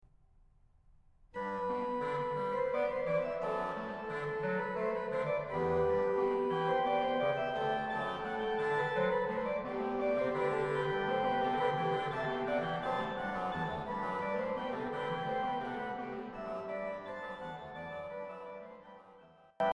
L’organo-orchestra Lingiardi 1877 di S. Pietro al Po in Cremona